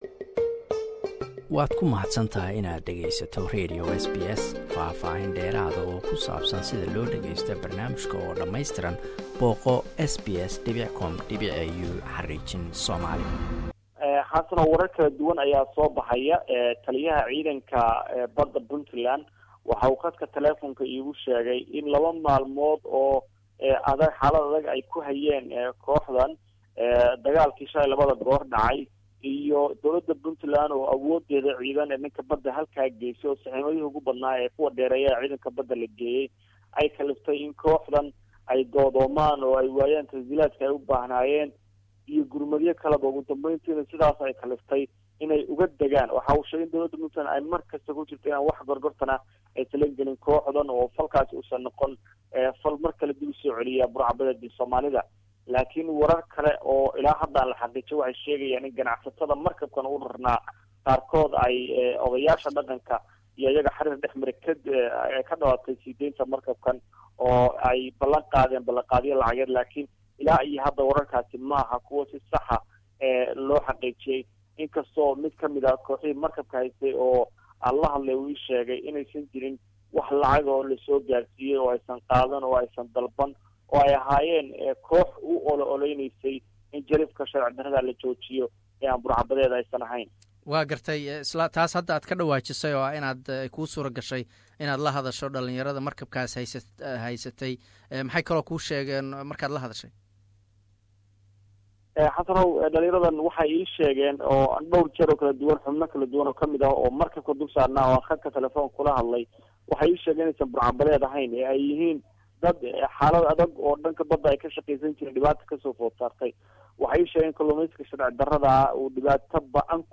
Tan ayaa timid kadib markii ciidanka bada ay xabado isdhaafsadeen rag agab u waday raga afddubayaasha. Waxaan ka waraysanay wariye ku sugan caasimada Puntland ee garoowe.